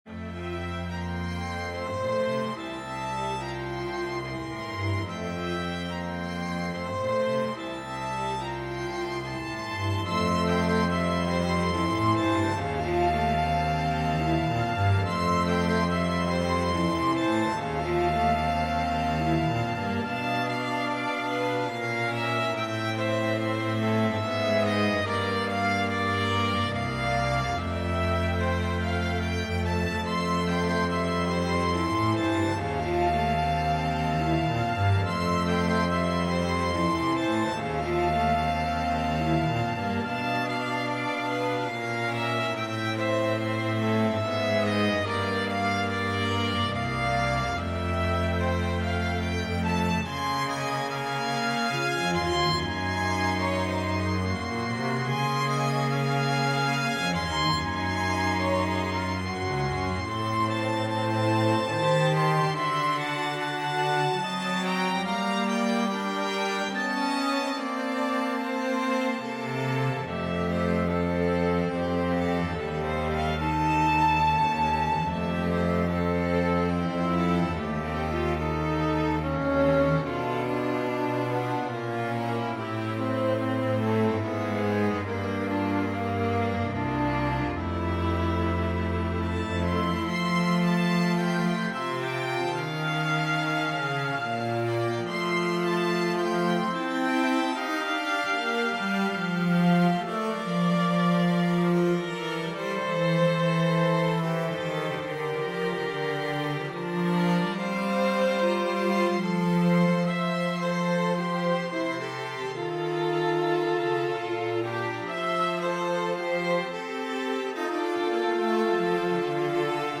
String Trio Buy now!